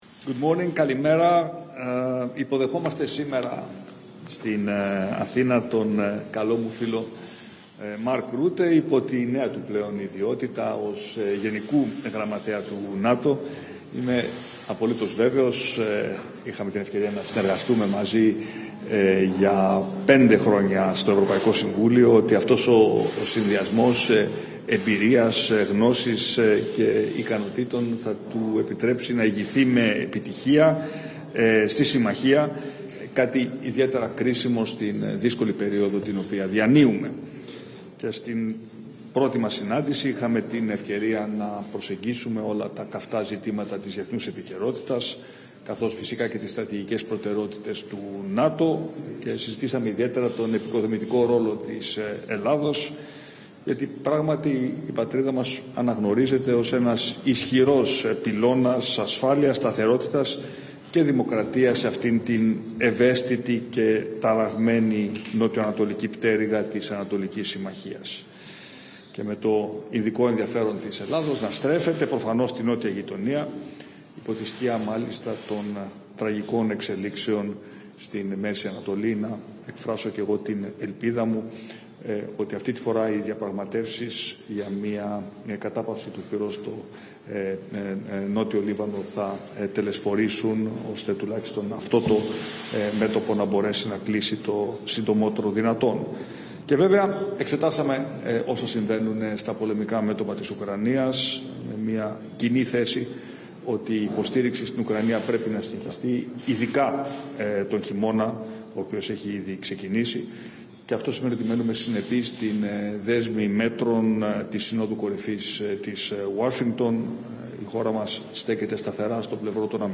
Ελληνικά - Κοινές δηλώσεις Τύπου του Γενικού Γραμματέα του ΝΑΤΟ Mark Rutte και του Πρωθυπουργού της Ελλάδας Κυριάκου Μητσοτάκη 26 Νοεμβρίου 2024 | λήψη mp3 Πρωτότυπο - Κοινές δηλώσεις Τύπου του Γενικού Γραμματέα του ΝΑΤΟ Mark Rutte και του Πρωθυπουργού της Ελλάδας Κυριάκου Μητσοτάκη 26 Νοεμβρίου 2024 | λήψη mp3